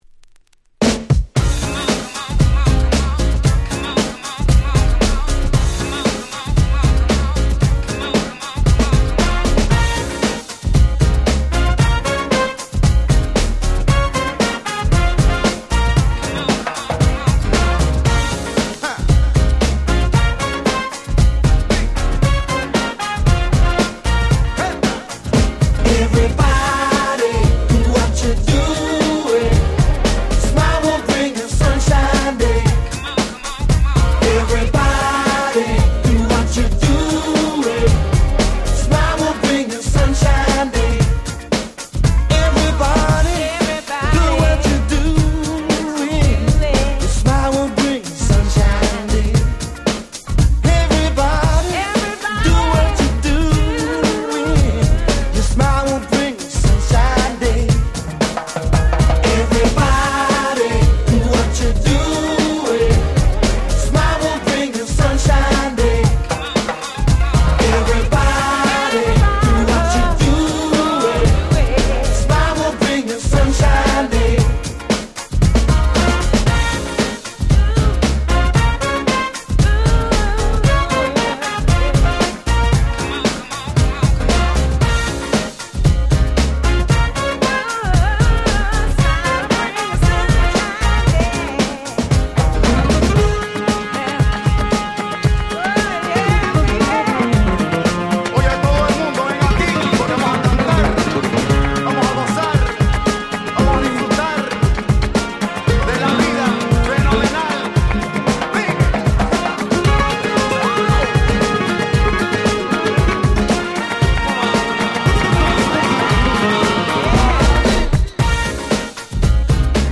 98' Super Hit Acid Jazz / UK Soul !!